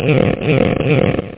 door2.mp3